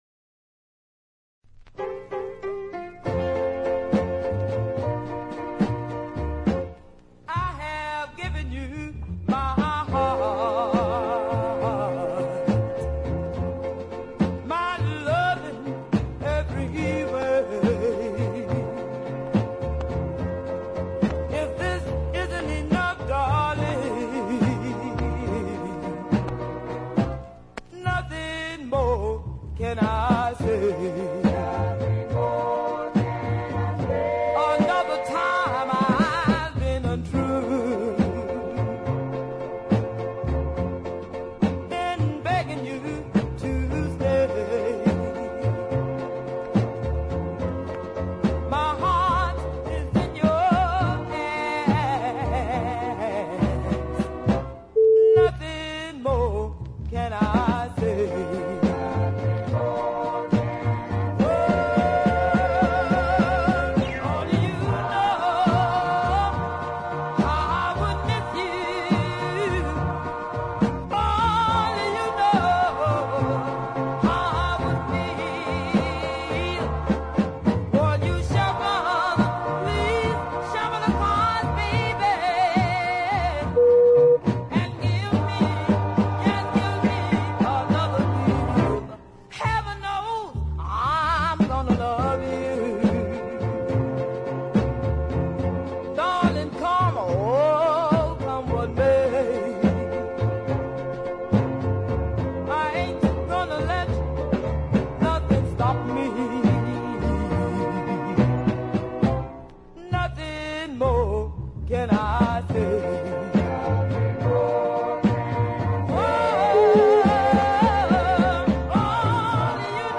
which has a sound somewhere between doo wop, R & B and soul